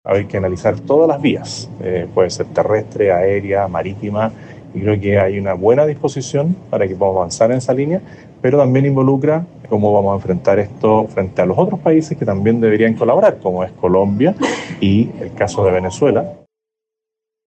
Al respecto, el presidente electo, José Antonio Kast, afirmó que se deben analizar todas las vías posibles para concretar esta política entre los países involucrados.